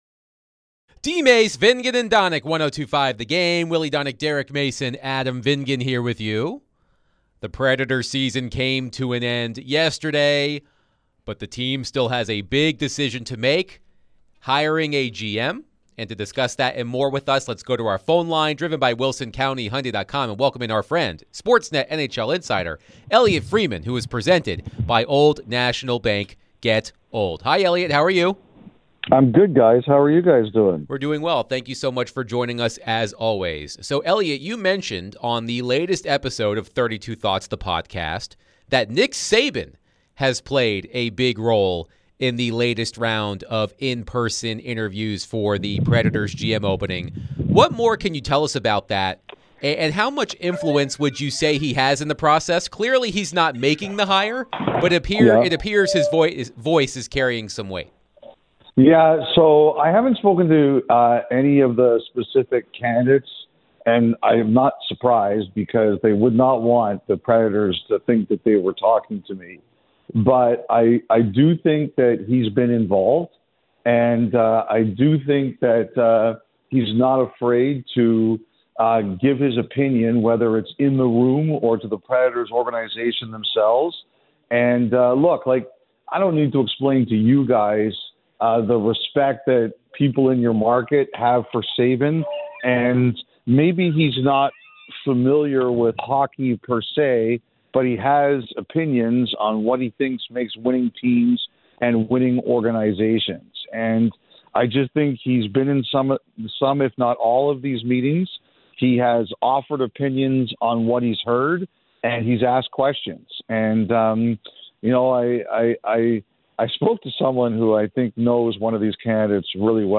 NHL Insider Elliotte Friedman joined DVD to discuss the Preds season, the next Preds GM, NHL Playoffs, and more.